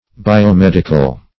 biomedical \bi"o*med`i*cal\